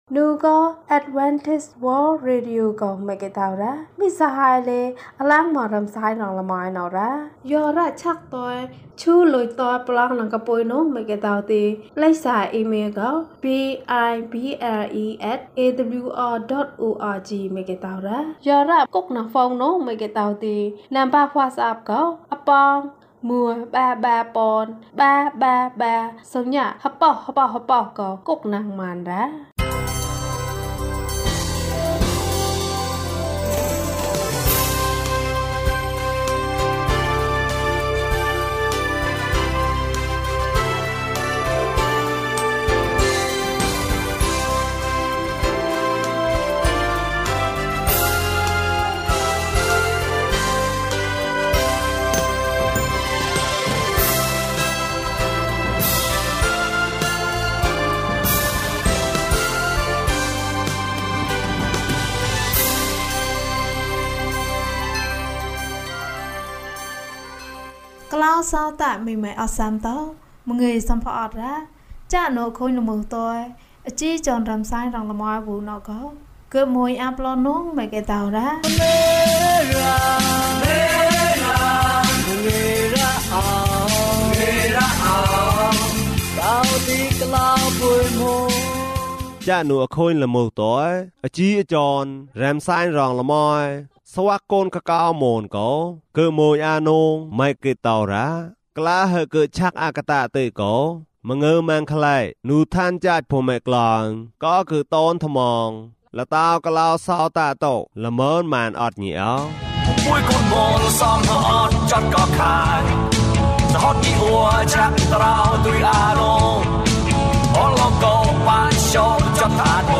ခရစ်တော်ထံသို့ ခြေလှမ်း။၀၁ ကျန်းမာခြင်းအကြောင်းအရာ။ ဓမ္မသီချင်း။ တရားဒေသနာ။